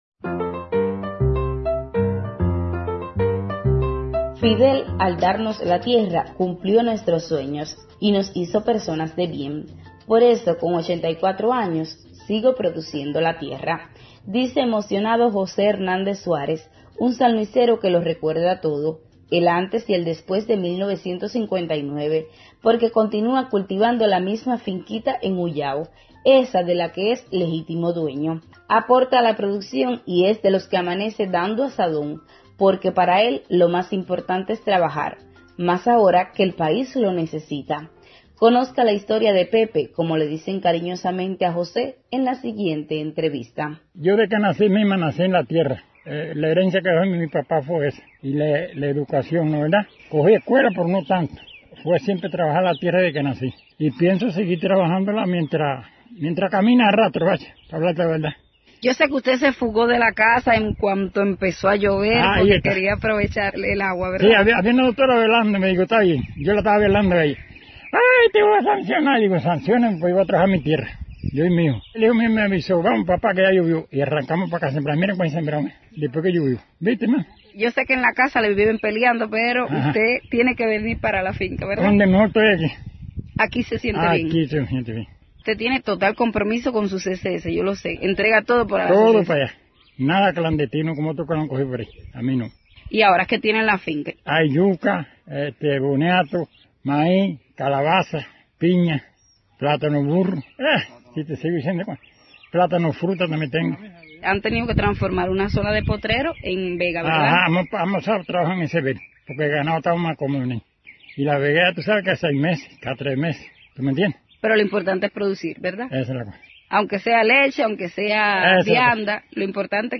(PROGRAMA EN COMPETENCIA. FESTIVAL MUNICIPAL DE LA RADIO)